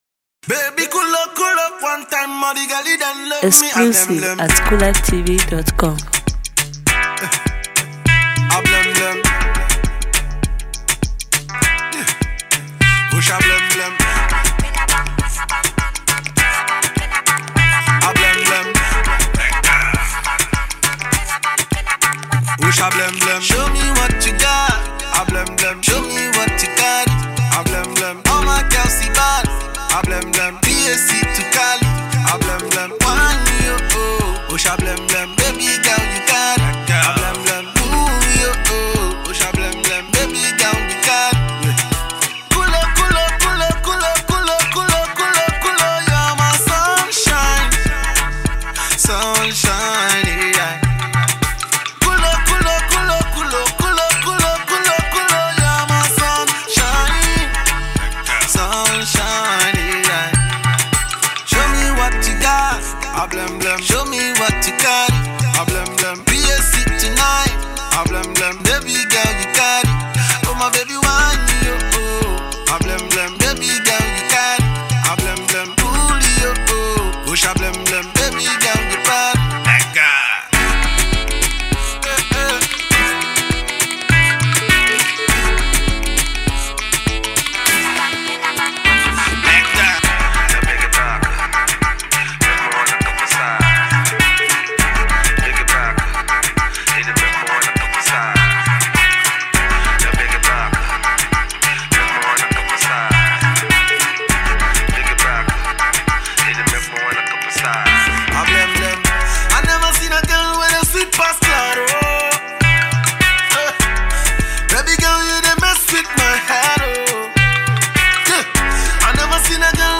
mid tempo track